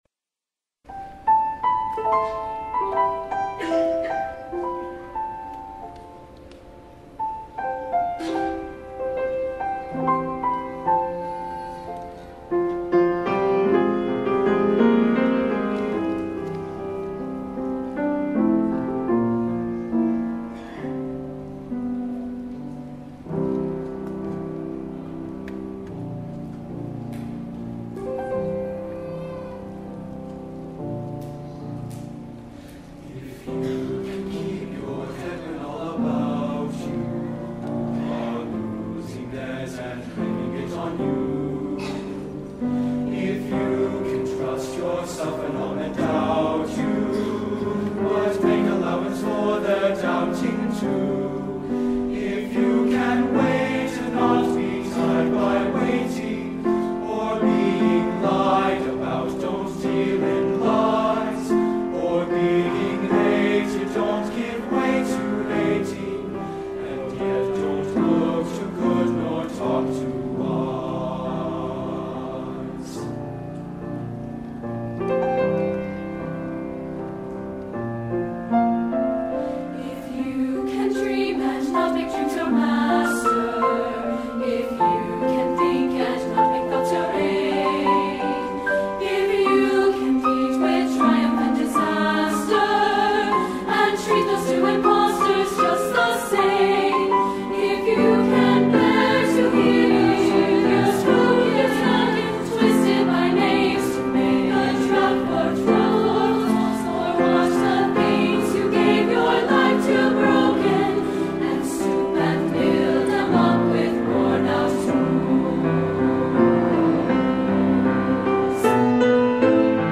SATB Choir and Piano